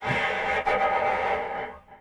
Player_UI [72].wav